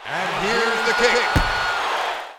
One of the other sound packages is called Football and I could have used its connect and disconnect sounds, but they fit in that category of cute sounds I warned about.
FB_Device_Connect_01St.wav